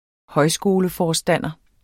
Udtale [ -ˌfɒːsdanˀʌ ]